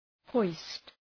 Προφορά
{hɔıst}